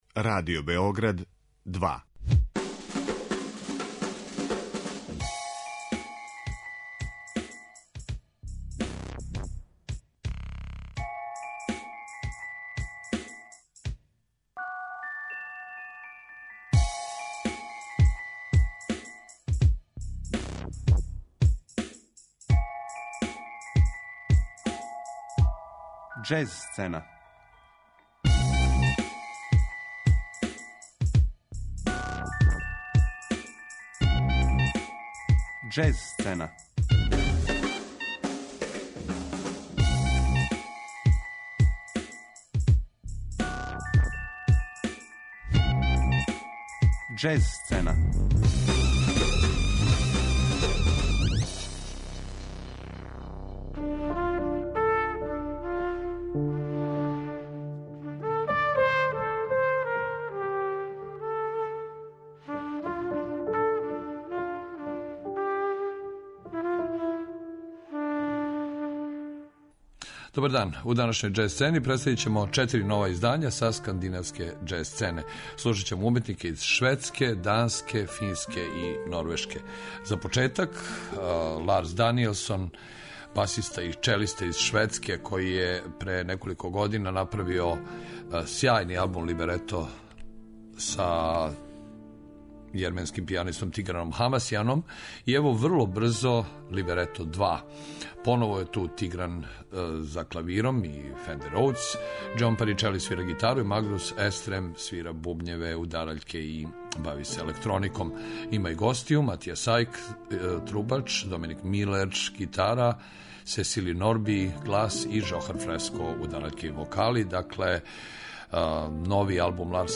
Аудио подкаст